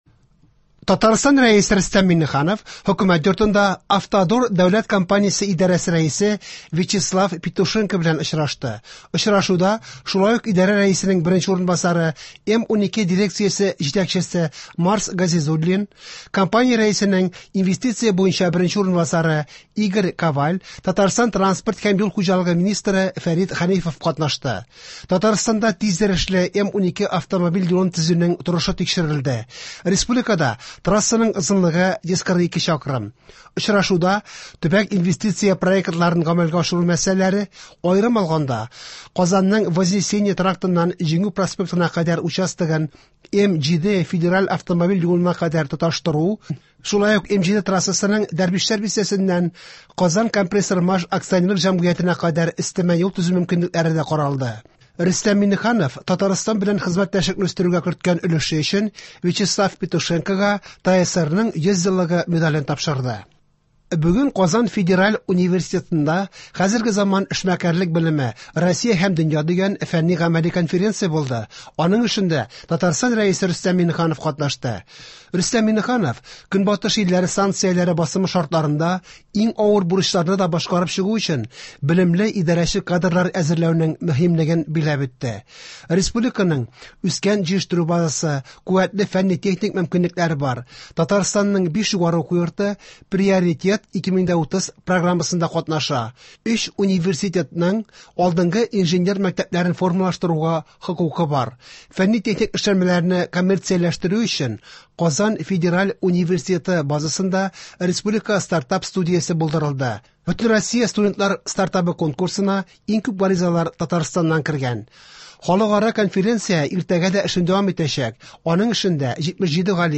Яңалыклар (23.06.23)